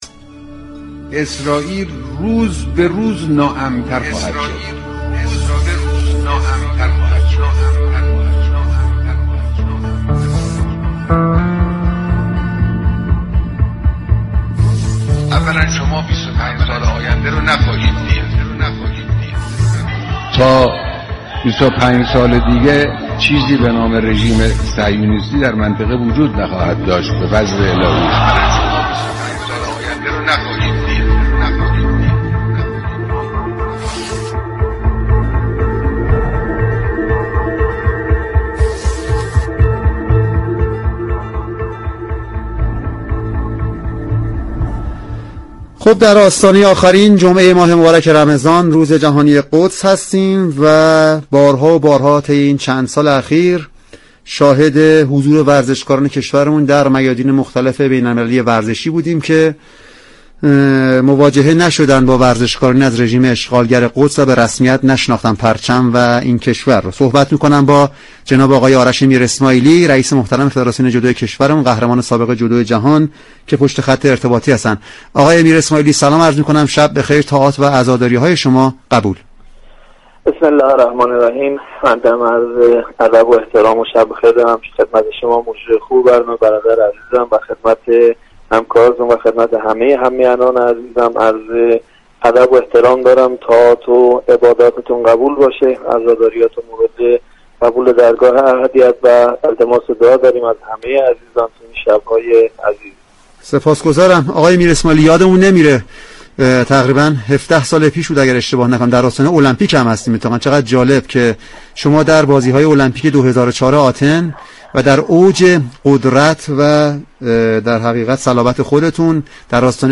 آرش میراسماعیلی، رئیس فدراسیون جودو در برنامه «تازه ها» 15 اردیبهشت به گفتگو درباره اهمیت دفاع از مردم مظلوم فلسطین پرداخت.